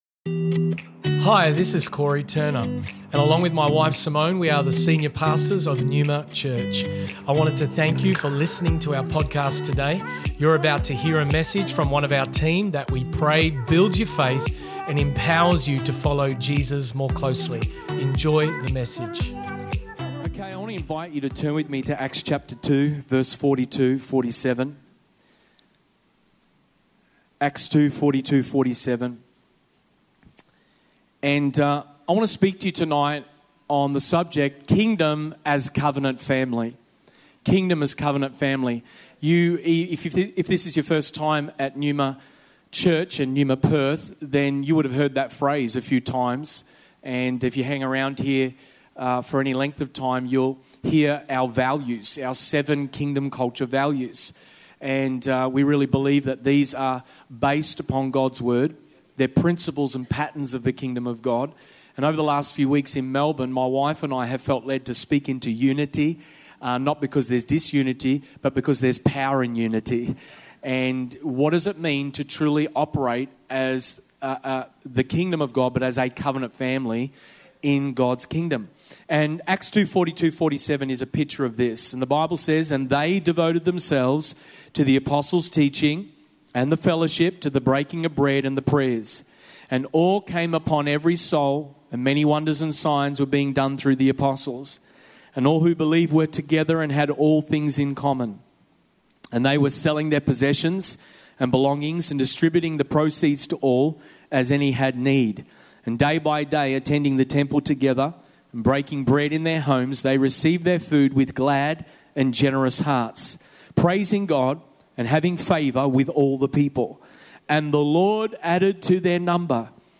This message was originally preached at NEUMA Church Perth on 30th May 2021.